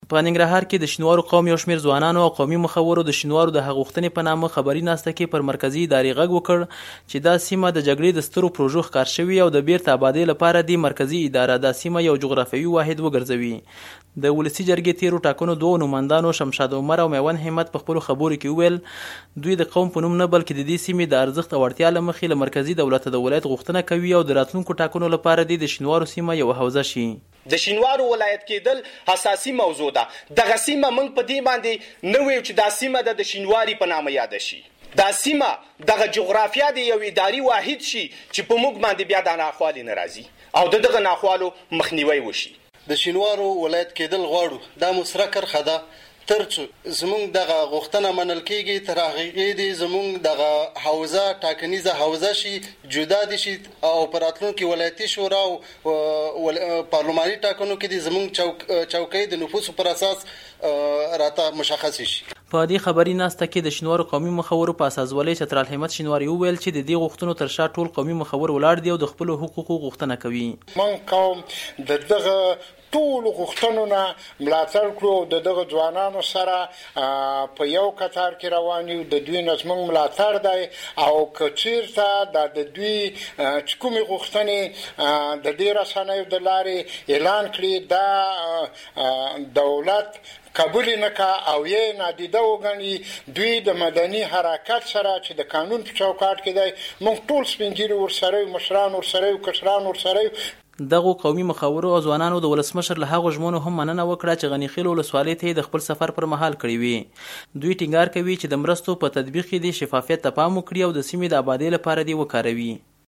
د ننګرهار راپور